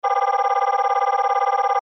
Gemafreie Sounds: Signale